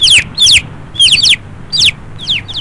Chaocard (bird) Sound Effect
chaocard-bird.mp3